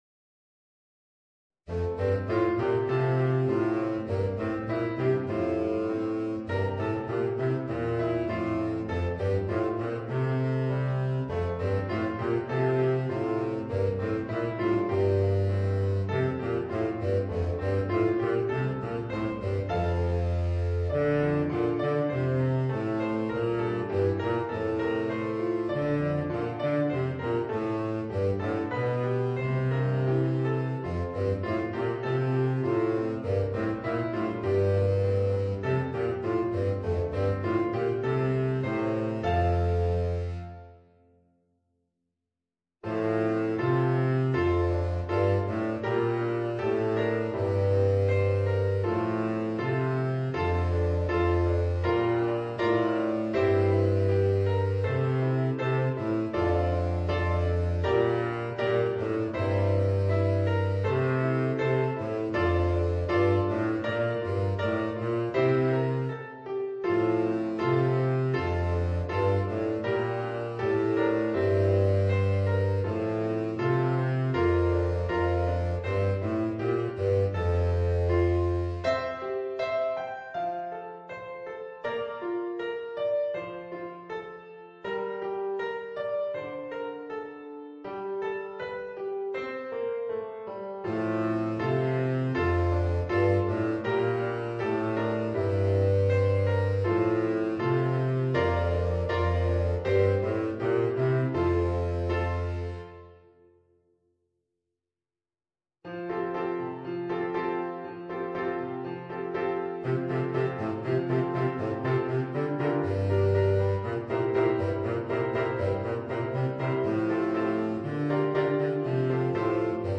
Voicing: Baritone Saxophone and Piano